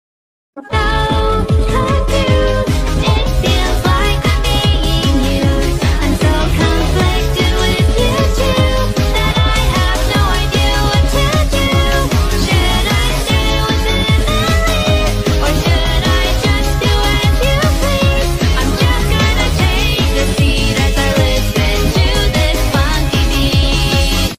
Sorry For The Terrible Quality Sound Effects Free Download